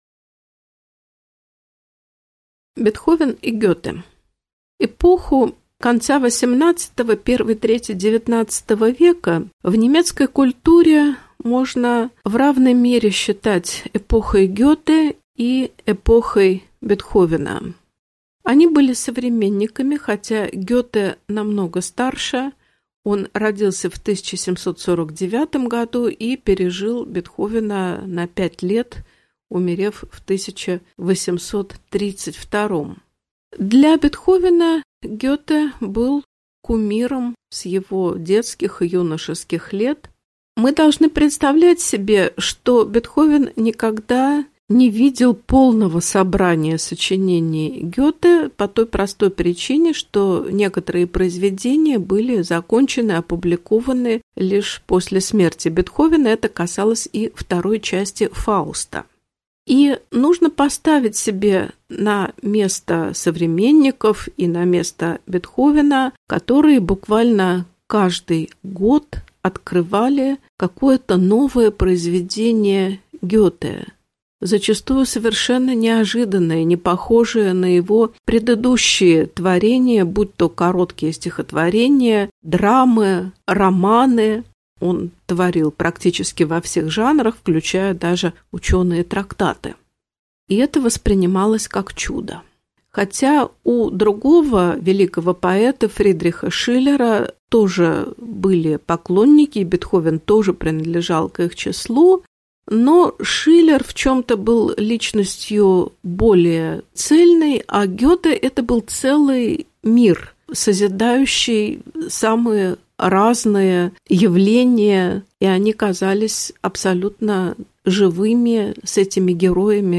Аудиокнига Лекция «Бетховен и Гёте» | Библиотека аудиокниг